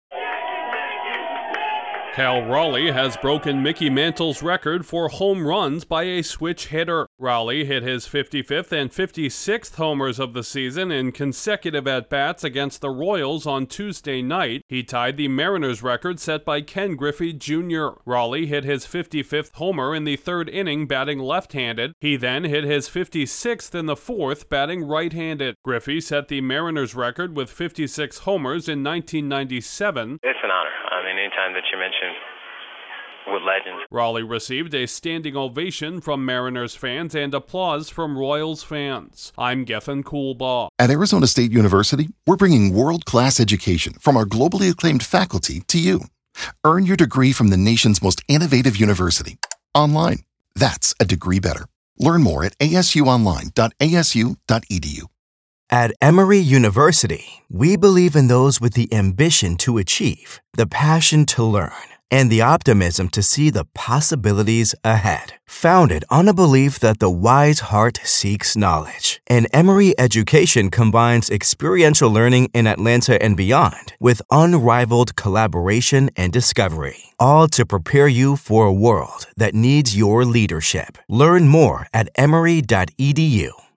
Baseball’s home run leader this season has chased down records set by two Hall of Famers. Correspondent